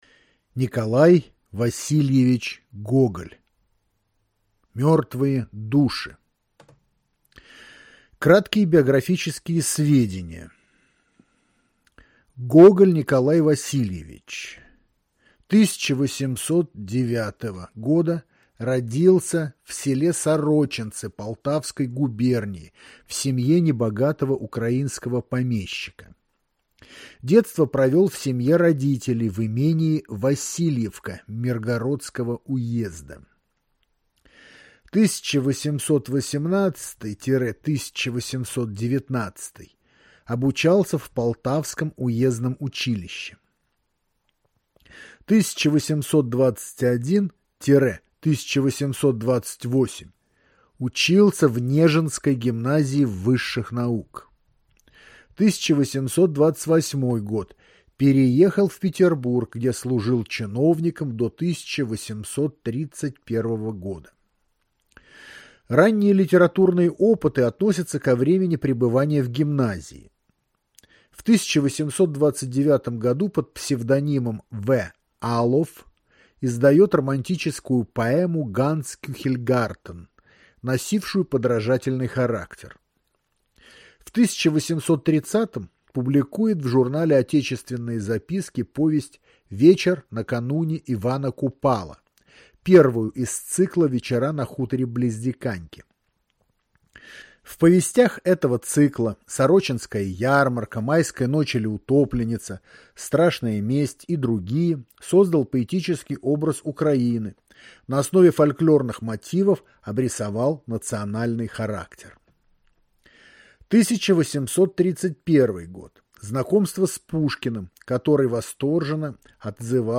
Аудиокнига Н. В. Гоголь «Мертвые души».